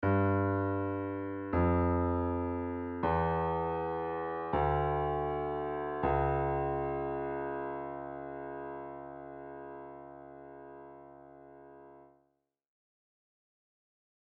Dark Scary Melodies
When you play a few notes LOW on the piano, they can sound quite dark.
Piano Notes
Play these notes as LOW as you can on the piano
thriller1.mp3